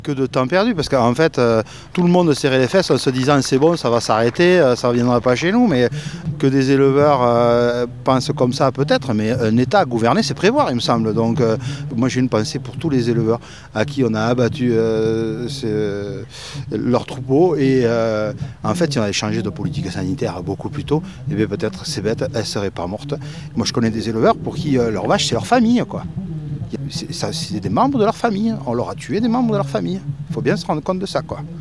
Un moment d’échange entre le préfet, des élus, des éleveurs et des représentants syndicaux.